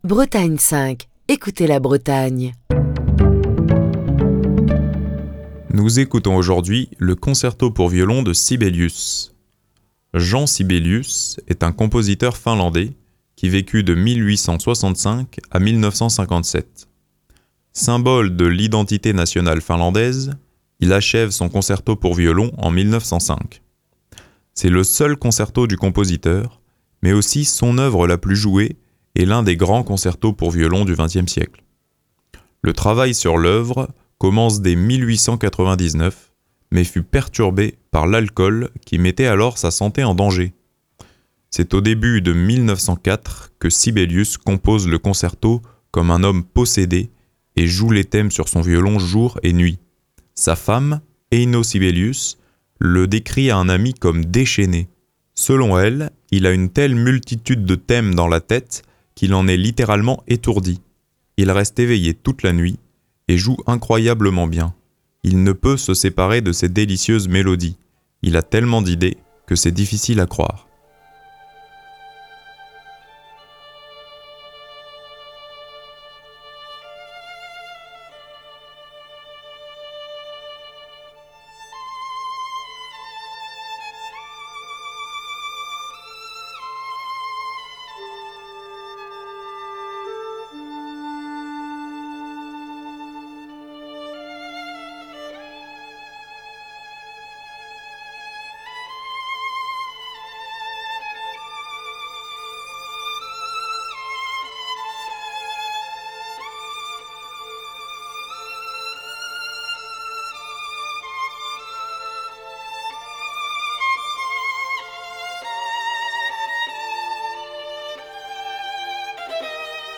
Le sombre et merveilleux concerto pour violon
redoutable de virtuosité